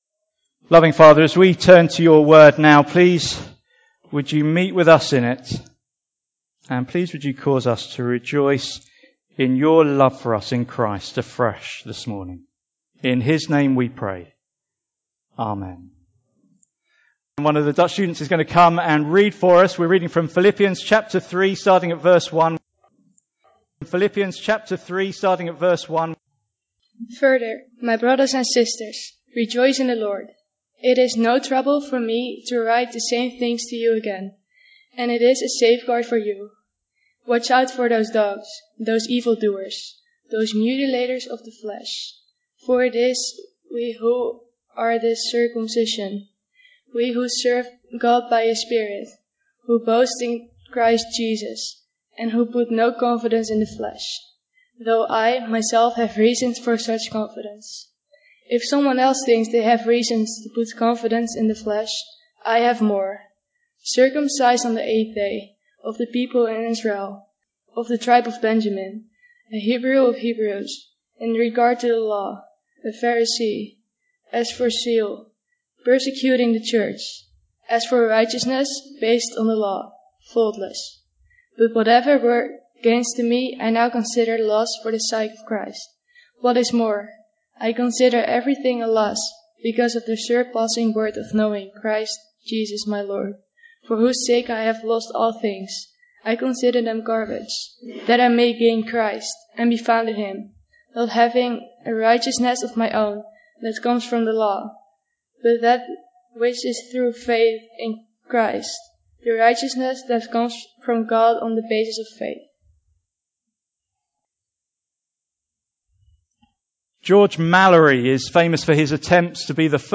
Passage: Philippians 3:1-9 Service Type: Sunday Morning